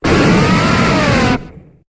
Cri de Drakkarmin dans Pokémon HOME.